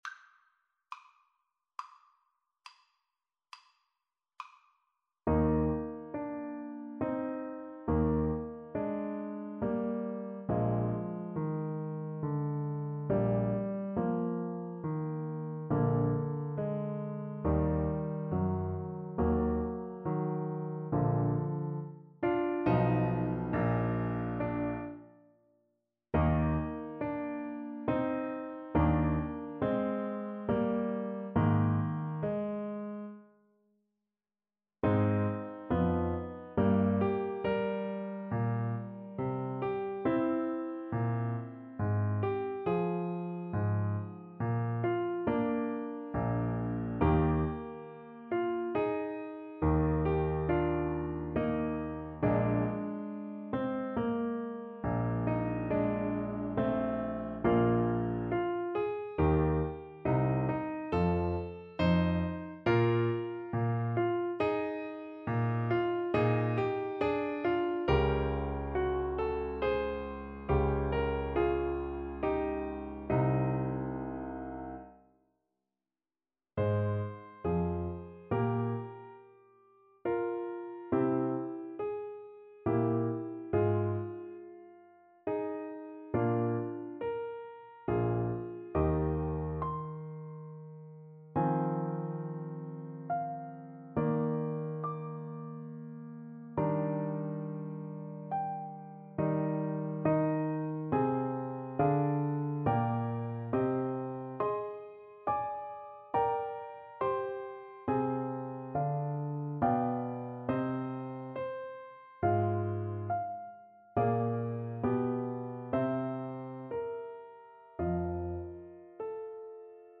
Play (or use space bar on your keyboard) Pause Music Playalong - Piano Accompaniment Playalong Band Accompaniment not yet available reset tempo print settings full screen
Eb major (Sounding Pitch) F major (Trumpet in Bb) (View more Eb major Music for Trumpet )
6/4 (View more 6/4 Music)
Andante =c.84 =69
Classical (View more Classical Trumpet Music)